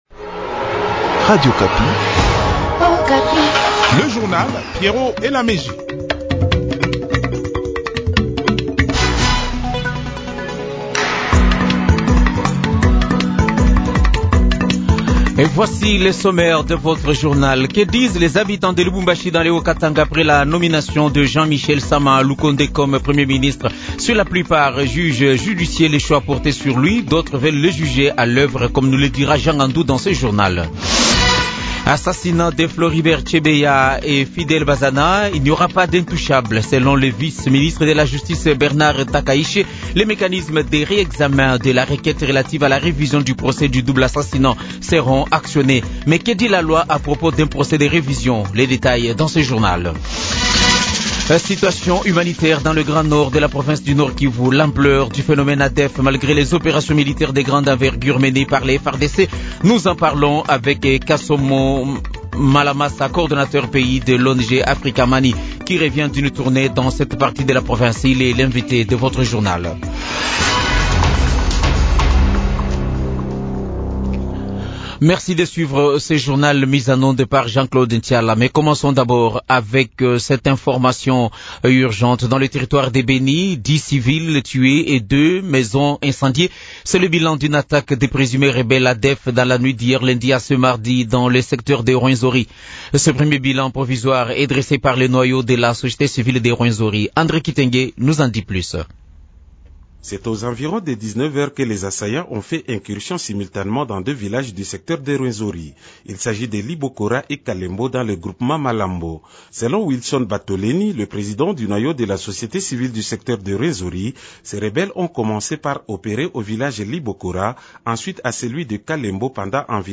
Journal Français Midi
2.Kinshasa : nomination premier ministre, vox-pop